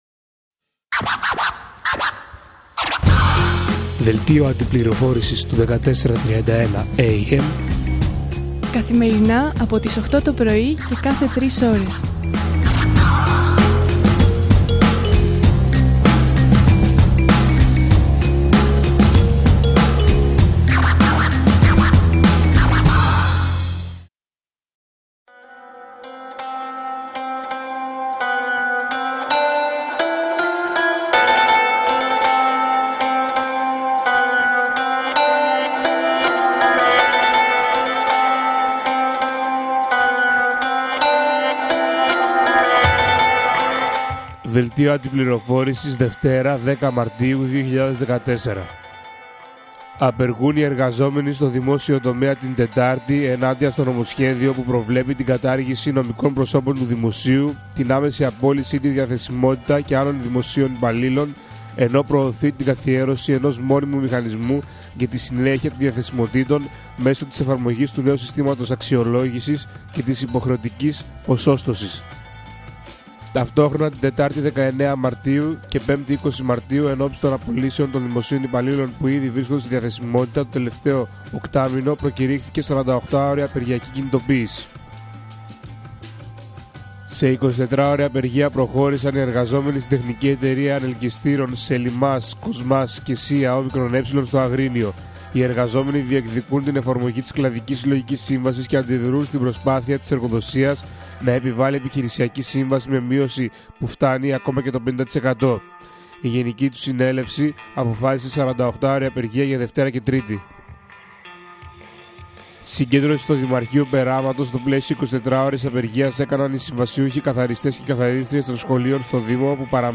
Δελτίο Αντιπληροφόρησης